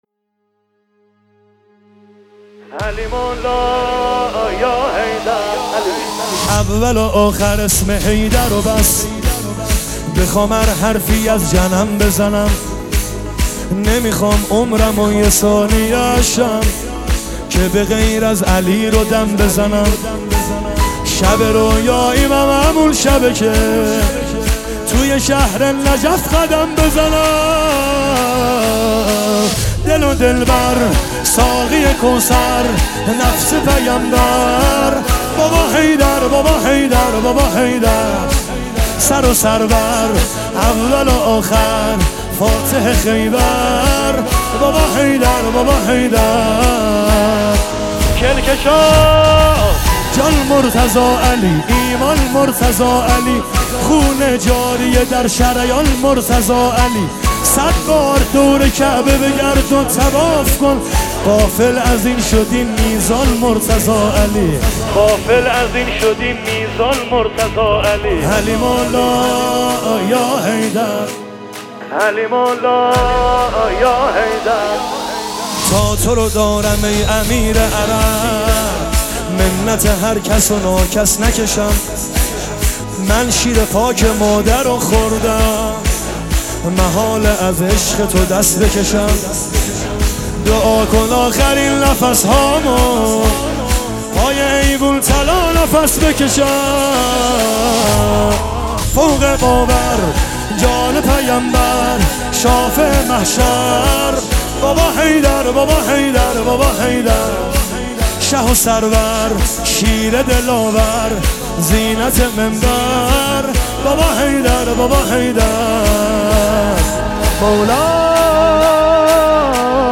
به مناسبت فرارسیدن عید غدیر خم